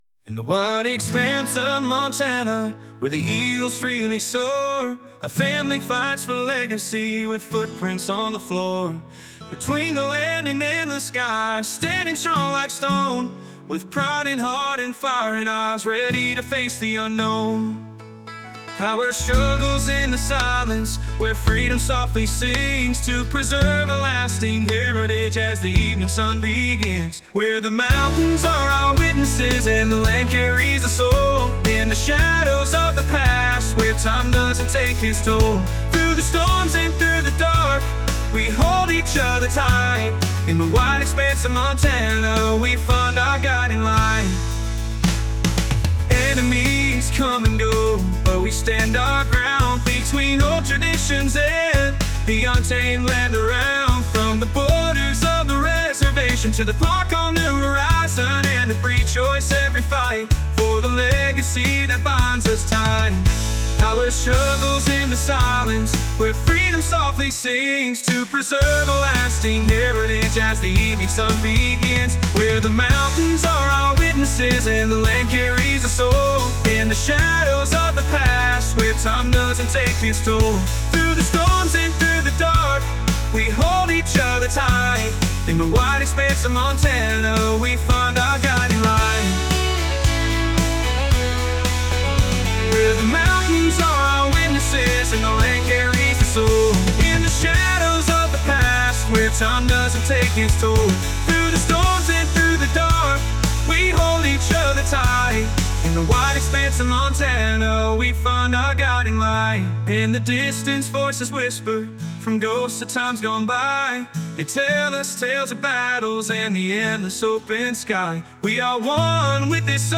Dieses Mal habe ich mit ChatGPT einen Songtext zu einer Serie schreiben lassen, den ich dann mit Suno vertont habe. Kleiner Tipp: Es geht um eine Serie, die gerade auf ihr Ende zusteuert.